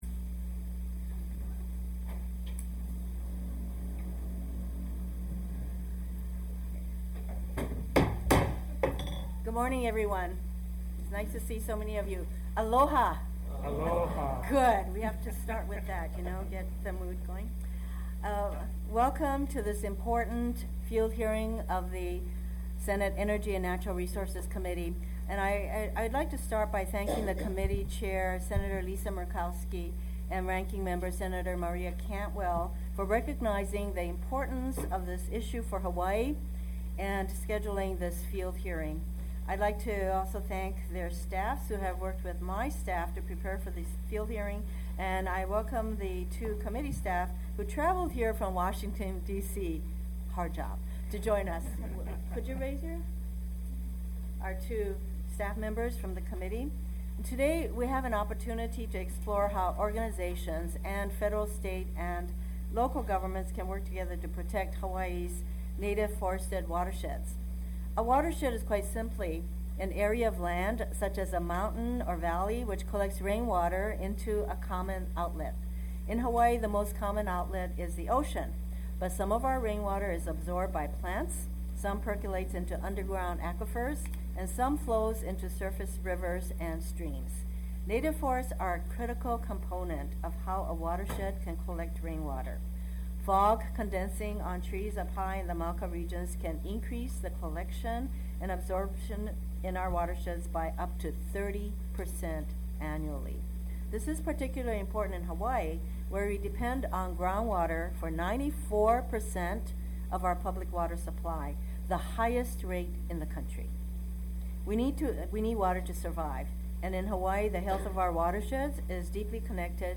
The Senate Committee on Energy and Natural Resources will hold a hearing on Tuesday, October 18, 2016, at 10:00 a.m. HST at the Hawaii State Capitol located at 415 South Beretania Street, Room 325, in Honolulu, Hawaii.
Sen. Mazie Hirono (D-Hawaii) will chair the field hearing.